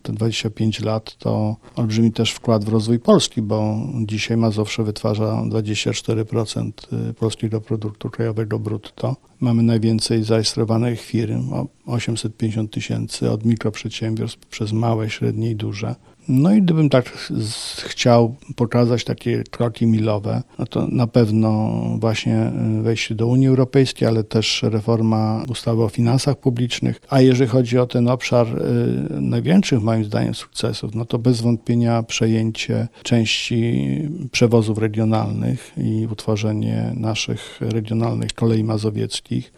Marszałek województwa, Adam Struzik mówi, że dzisiaj Mazowsze jest synonimem sukcesu.